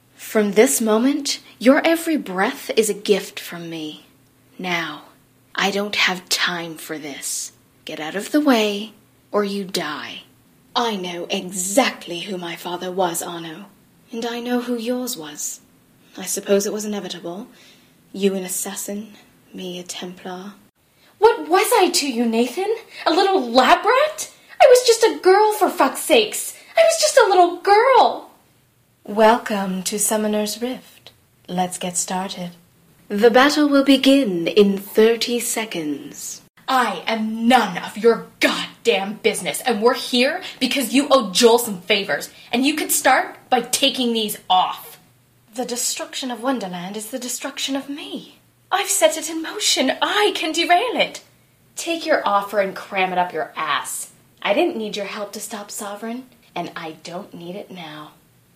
Videogames - EN